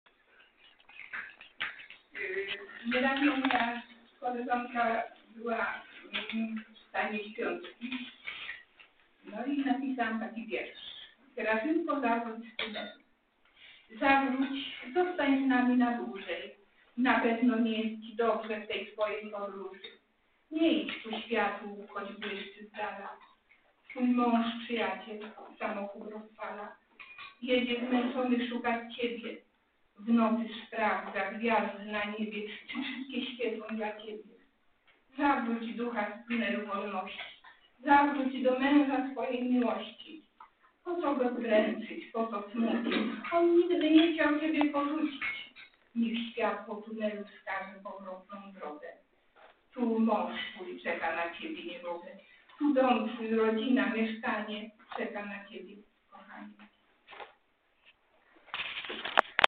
O godzinie 10. rozpoczęło się spotkanie członków i sympatyków Grupy Literackiej "Gronie".
(przrepraszamy za zakłócenia i niezbyt dobrą jakość nagrania)